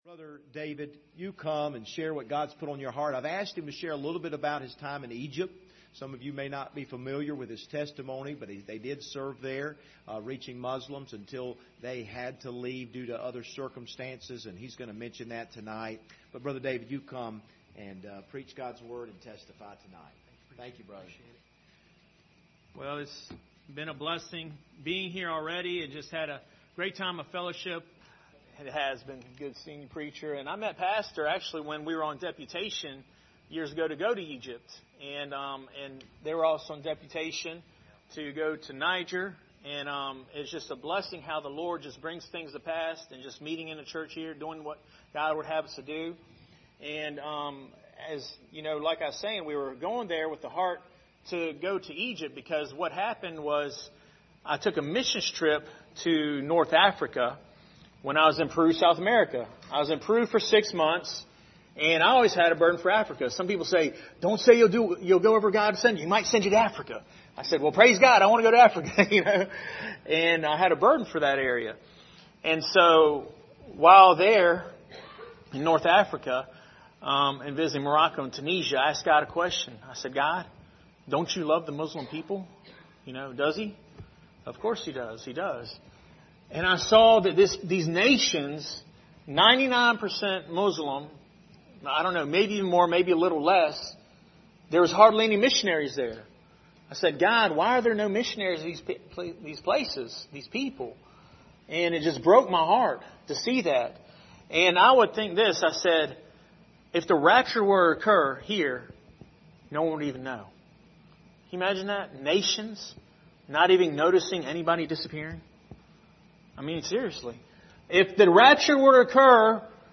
General Service Type: Sunday Evening Topics: missions « Christ is Worthy Protectors of the Peace of God